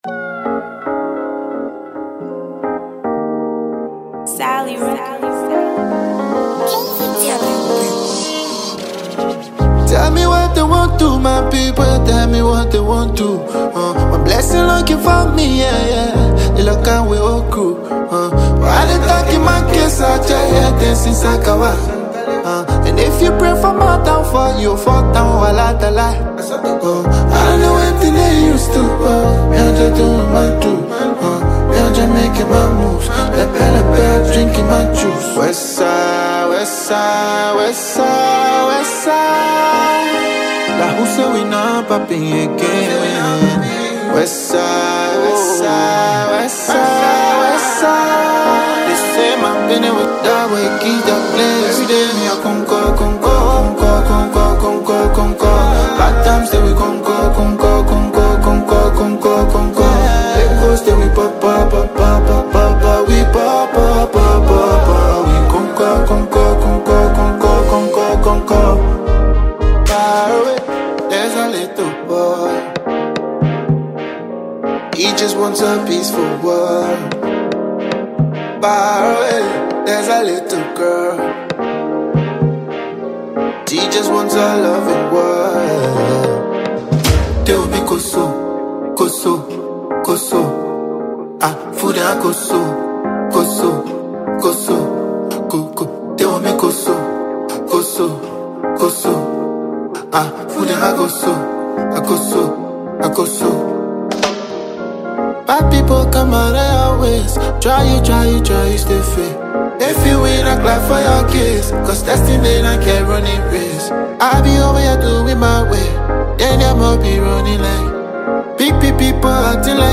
/ Instrumental, Liberian Music, RnB / By
R&B
powerful vocals over a minimalist beat